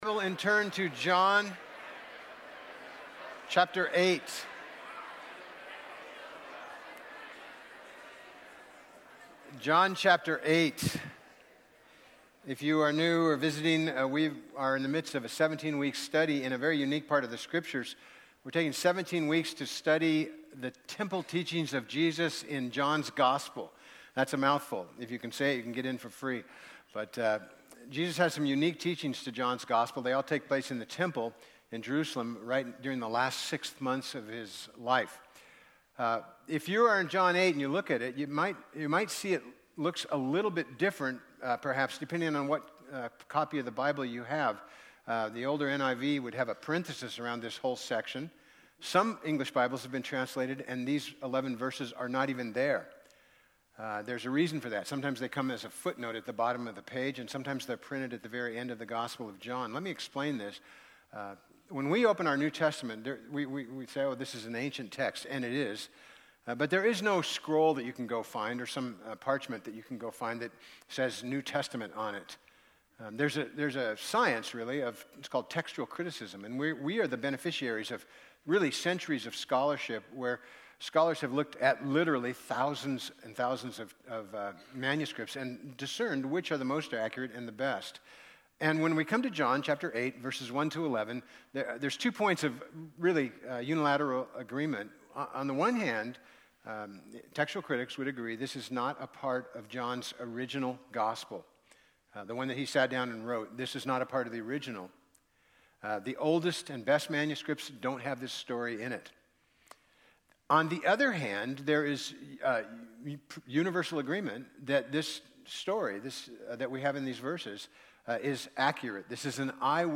Bible Text: John 8:1-11 | Preacher